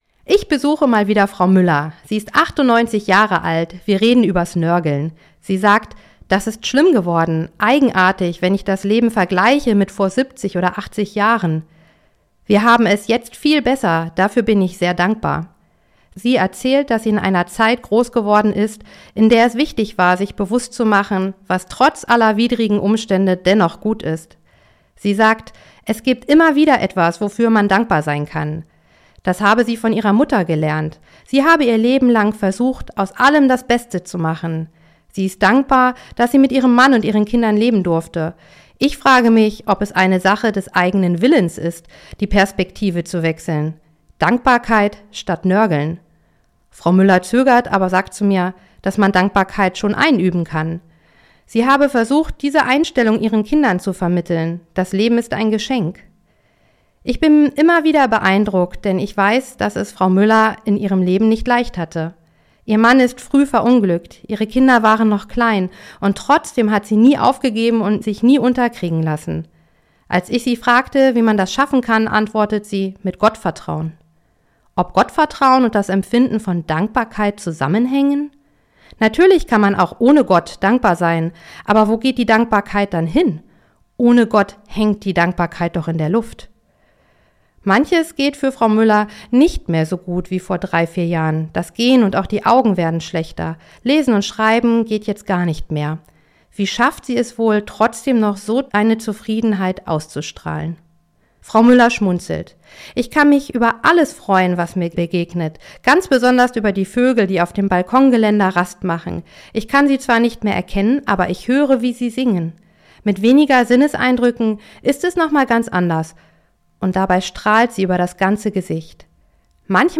Radioandacht vom 30. Juli